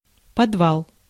Ääntäminen
Synonyymit подземелье Ääntäminen Tuntematon aksentti: IPA: /pɐdˈval/ Haettu sana löytyi näillä lähdekielillä: venäjä Käännöksiä ei löytynyt valitulle kohdekielelle.